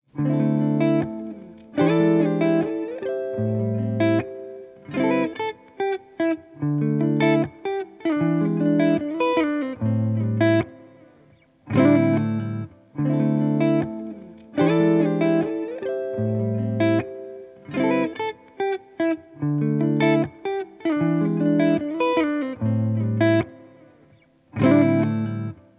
• 18 authentic guitar loops (with Stems: 90 total samples)